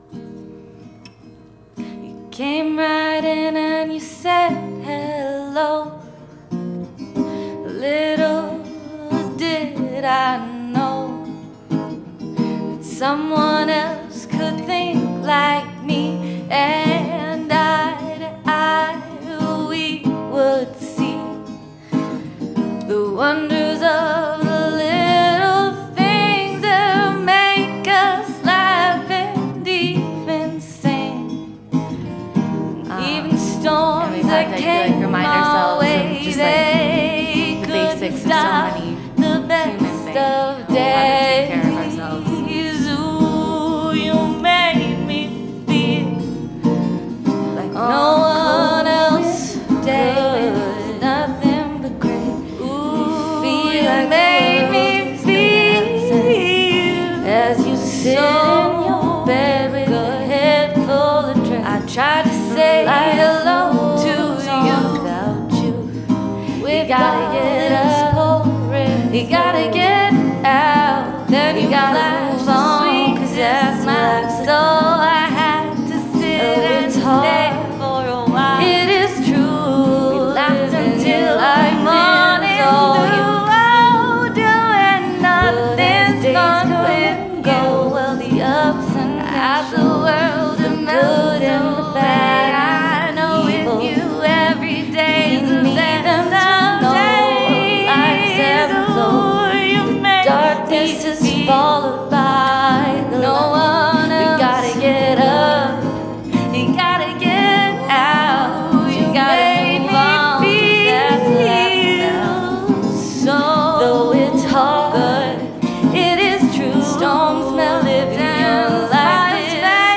Mercury Café Open Mic Nite